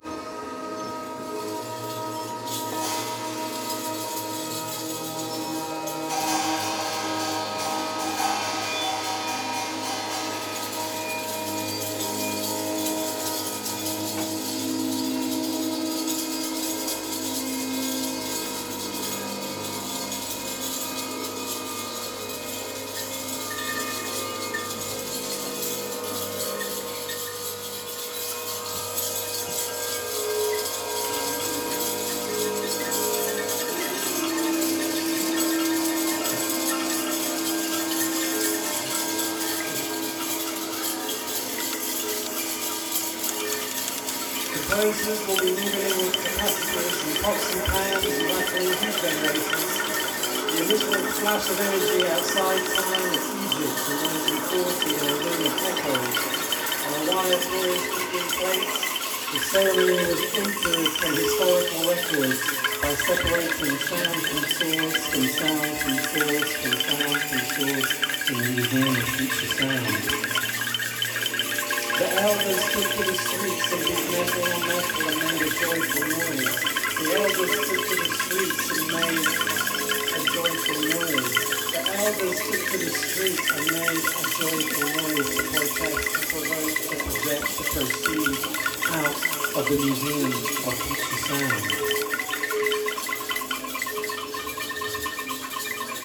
Threads of noise, tape loops, and samples.